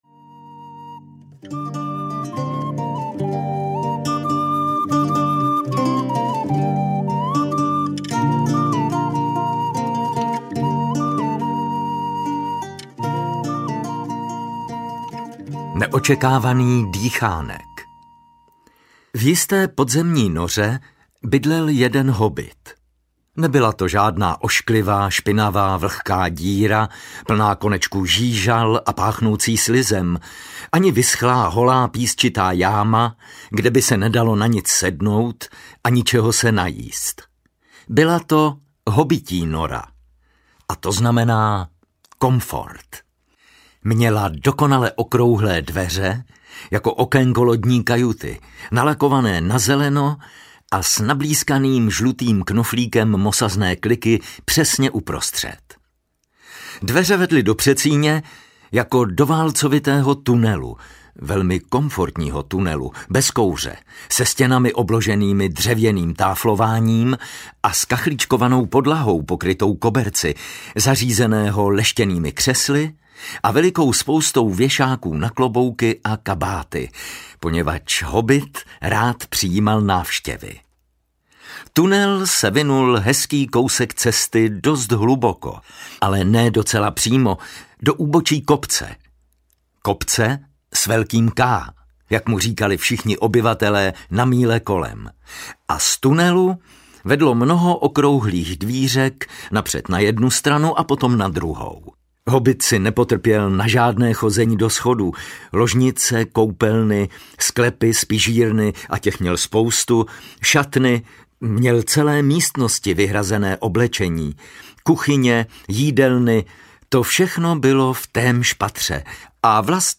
Hobit audiokniha
Ukázka z knihy